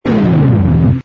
cruise_backfire.wav